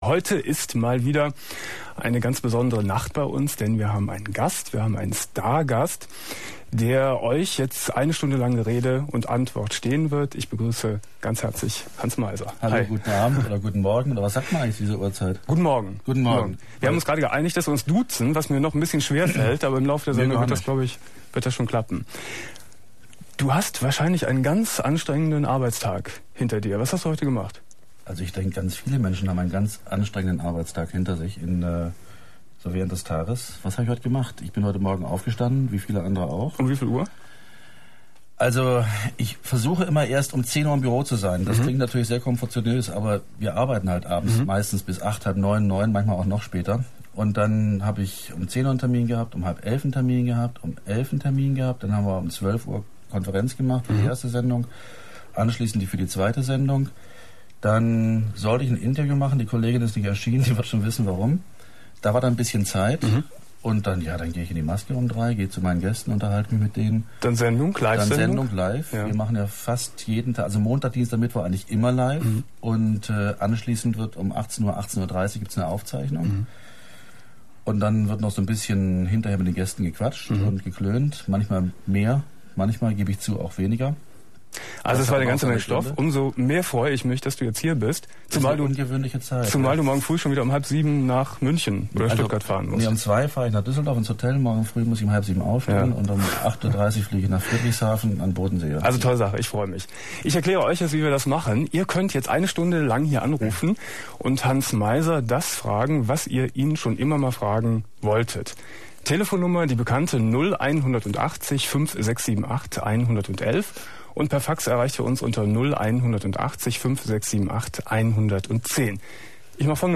28.01.1999 Domian Studiogast: Hans Meiser ~ Domian Talkradio - Das Archiv Podcast